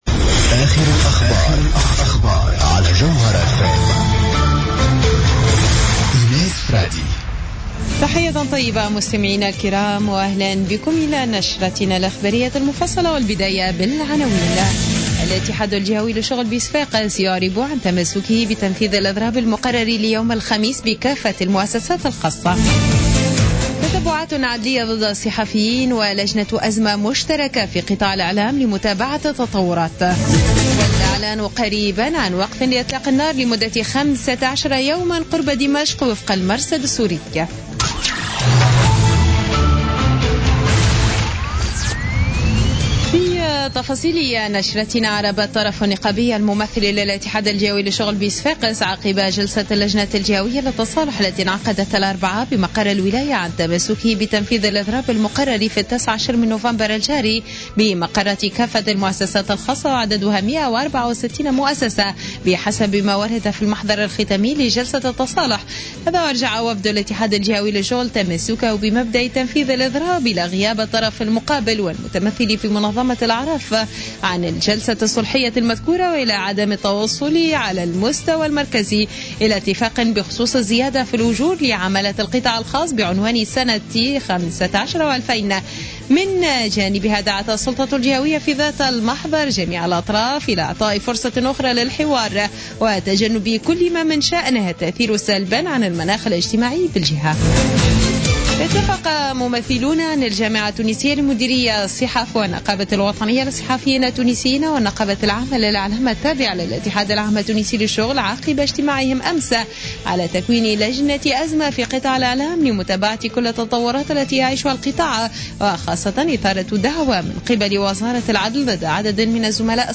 نشرة أخبار منتصف الليل ليوم الخميس 19 نوفمبر 2015